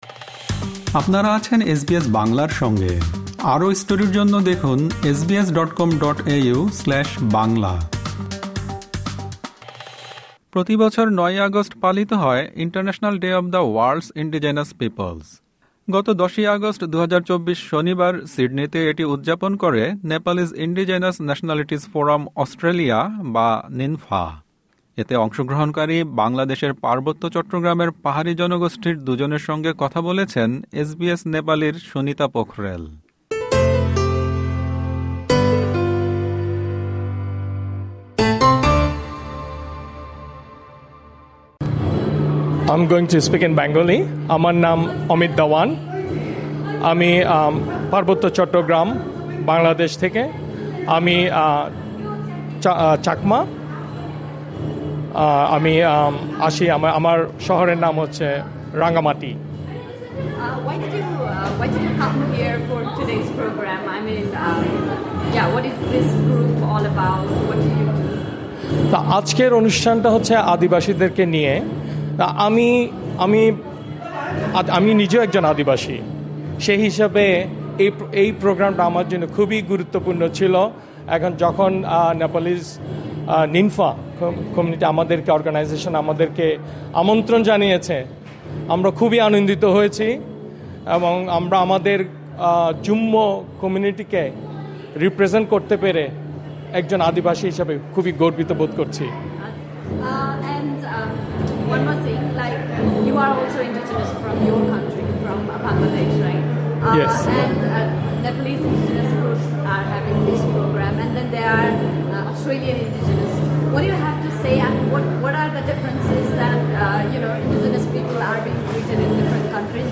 প্রতিবছর ৯ আগস্ট পালিত হয় ইন্টারন্যাশনাল ডে অব দ্য ওয়ার্ল্ড’স ইনডিজেনাস পিপলস। গত ১০ আগস্ট, ২০২৪ শনিবার সিডনিতে এটি উদযাপন করে নেপালিজ ইনডিজেনাস ন্যাশনালিটিজ ফোরাম অস্ট্রেলিয়া (NINFA)। এতে অংশগ্রহণকারী বাংলাদেশের পার্বত্য চট্টগ্রামের পাহাড়ি জনগোষ্ঠীর দু’জনের সঙ্গে কথা বলেছেন